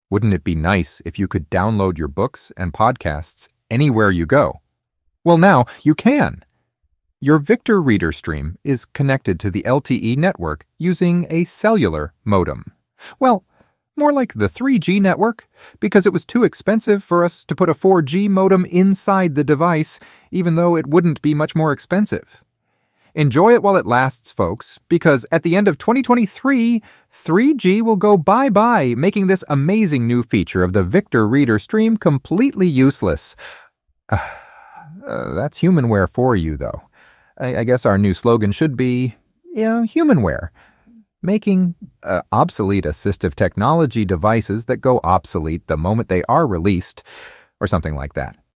labs Made with elevenlabs and the victor reader voice.